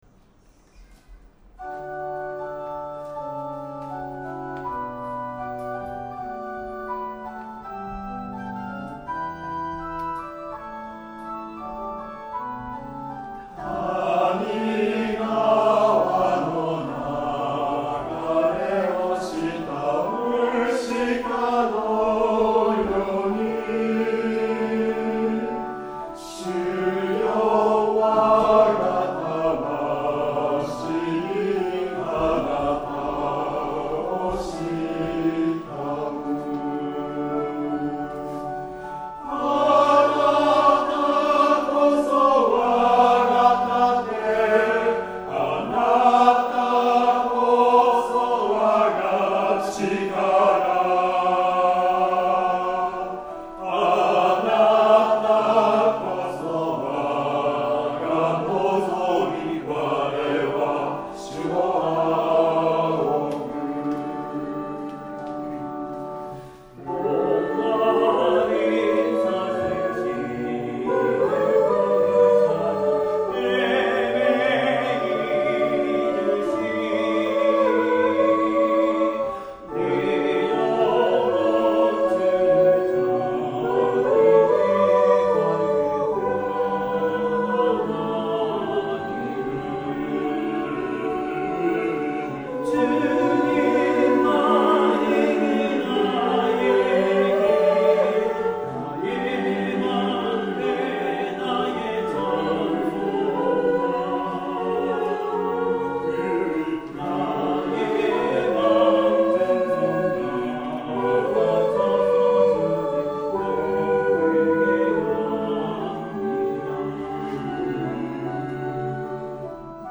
Tonality = D
♪   練習: 2024/9/1 聖歌隊練習 Take-2 全体 (リバーブ入 Mid-Room)